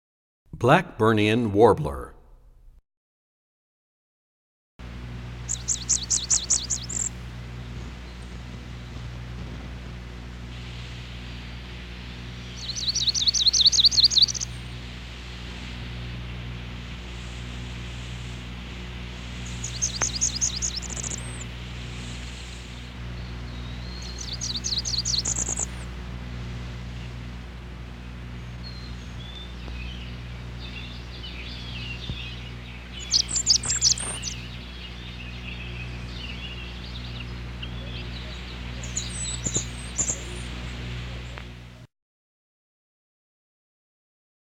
09 Blackburnian Warbler.mp3